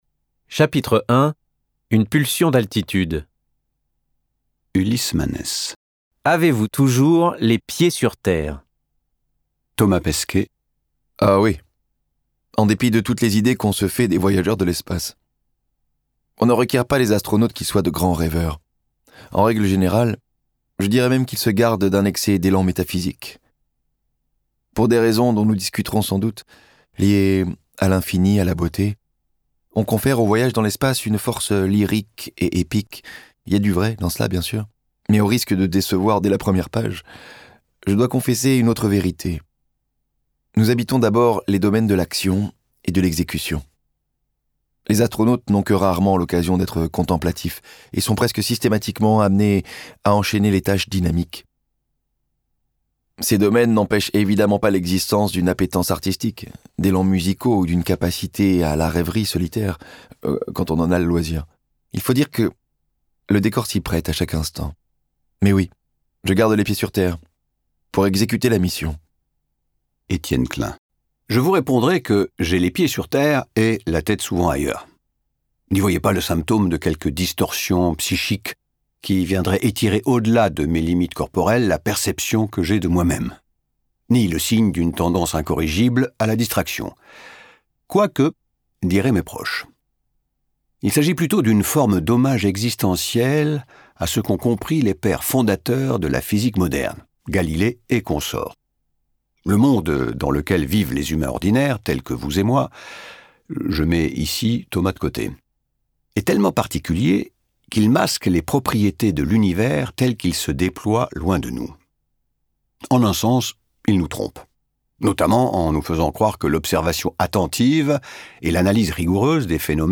« Eloges du dépassement » de Thomas Pesquet et Domnique Klein, lu par trois comédiens